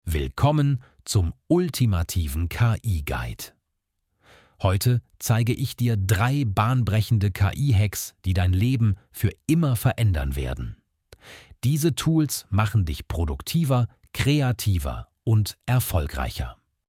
Automatisch generierte Videos mit deutscher KI-Stimme
• Stimme: Stefan (ElevenLabs German)
ElevenLabs TTS
🎤ElevenLabs TTS: Stefan Voice (Deutsche Native Speaker)